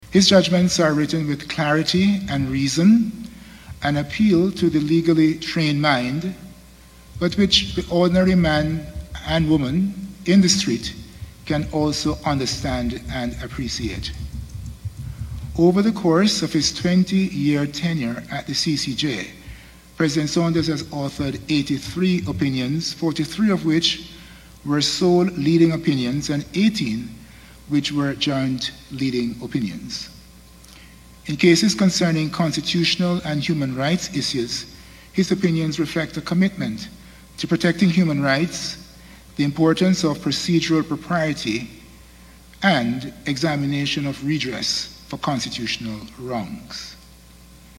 He was speaking during a Special Sitting of the CCJ on the occasion of the retirement of Justice Saunders.